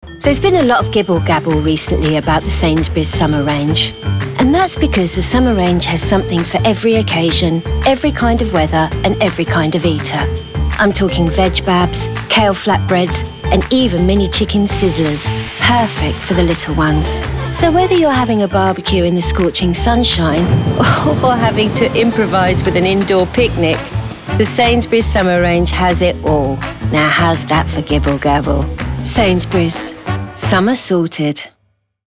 Voice Reel
Sainsbury's - Relatable, Playful, Welcoming